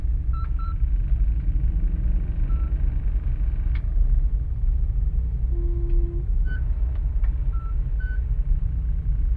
loading_hum.ogg